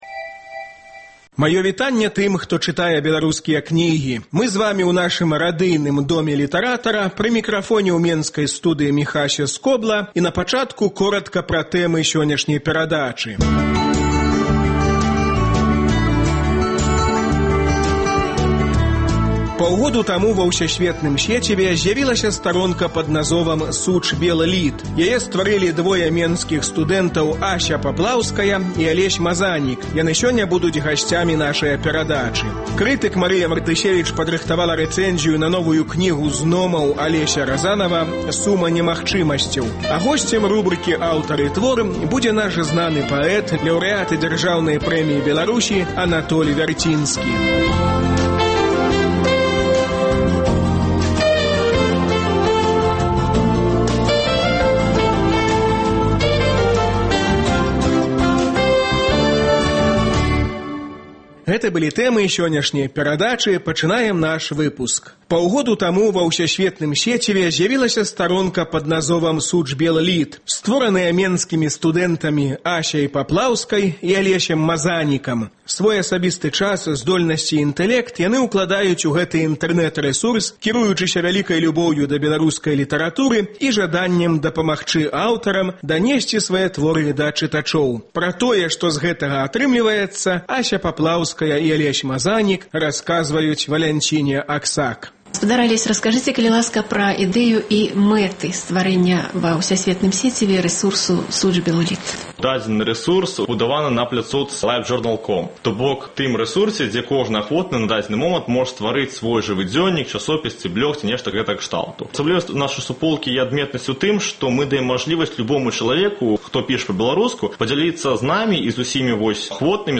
Крытык Марыя Мартысевіч выступае з рэцэнзіяй на новую кнігу Алеся Разанава. У рубрыцы “Аўтар і твор” свае новыя вершы чытае Анатоль Вярцінскі.